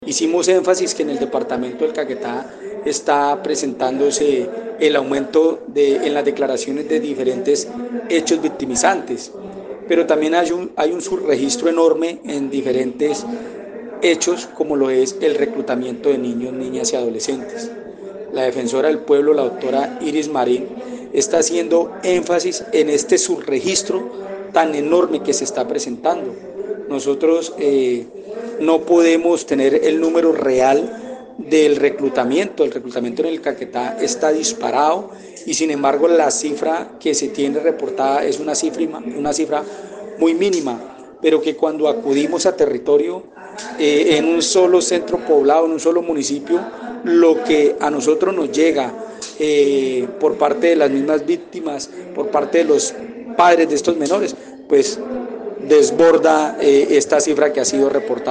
Así lo dio a conocer Edwin Leal, Defensor del Pueblo en Caquetá, al manifestar que regiones como Cartagena del Chaira, Solano, Solita, San Vicente del Caguan, Curillo, registran casos de reclutamiento forzado de menores de edad.